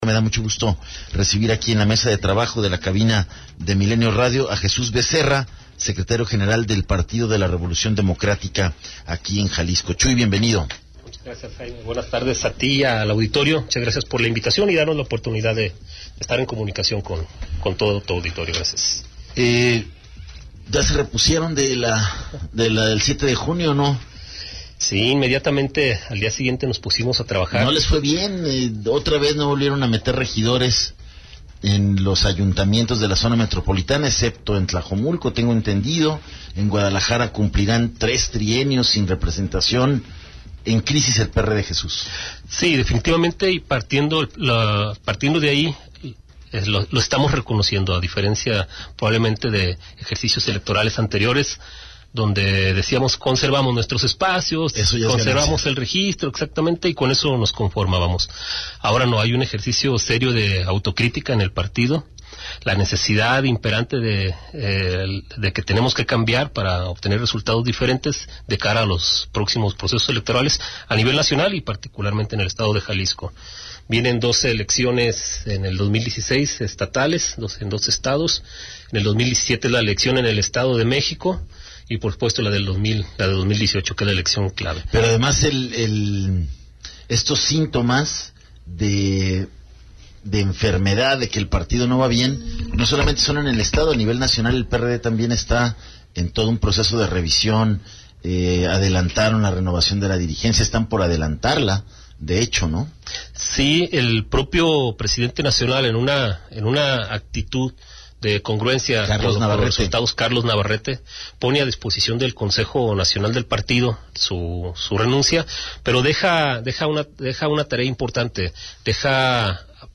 ENTREVISTA 030915